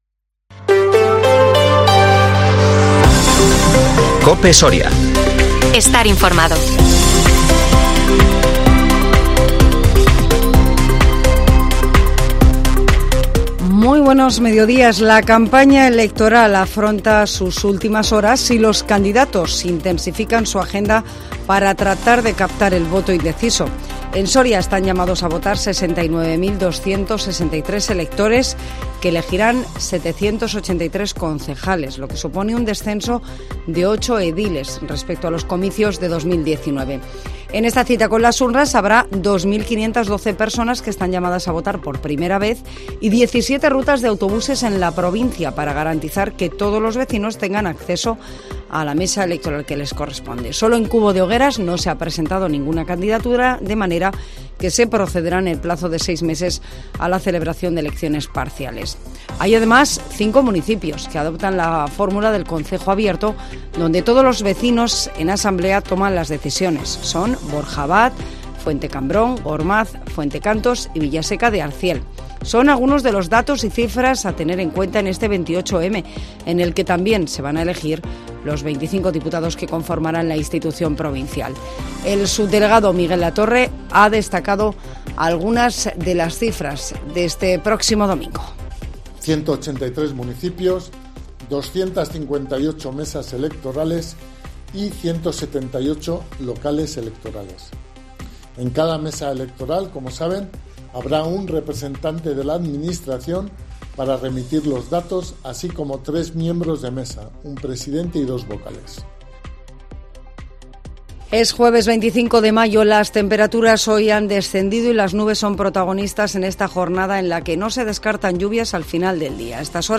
INFORMATIVO MEDIODÍA COPE SORIA 25 MAYO 2023